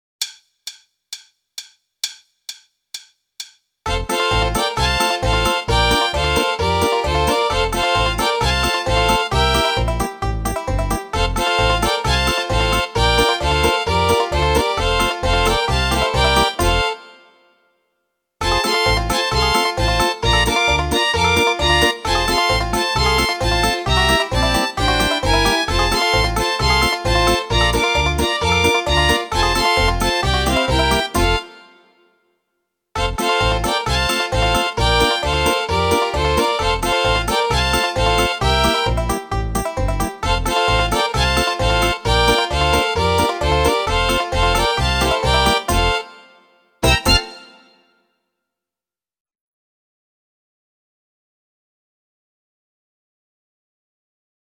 Meespeel CD
38. Country